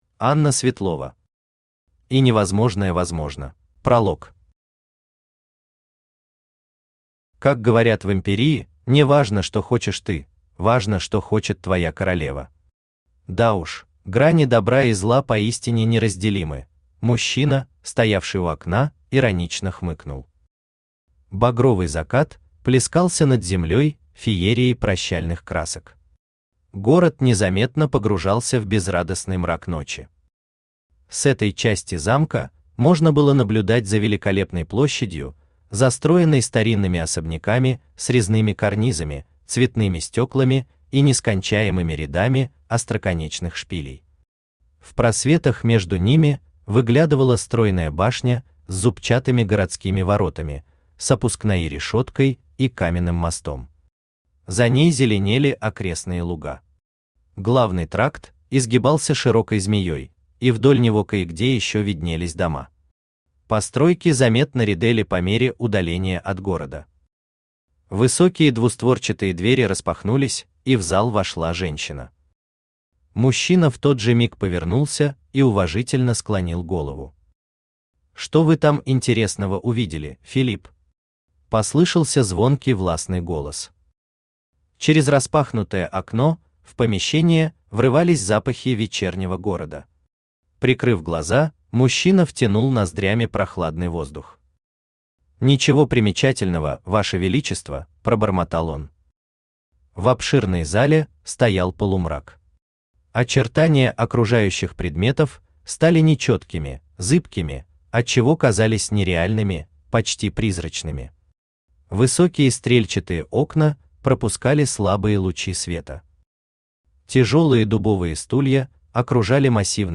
Читает: Авточтец ЛитРес